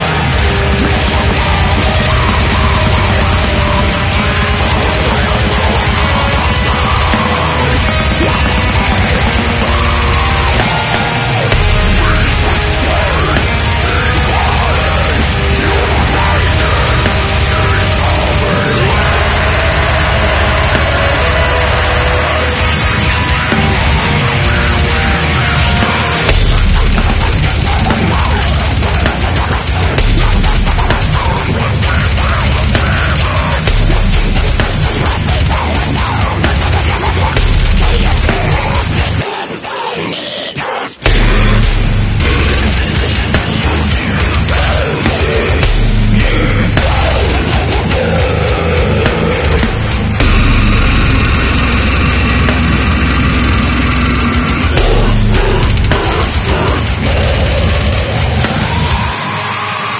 Sneezing metalheads be like… 🤘🤧🤘 sound effects free download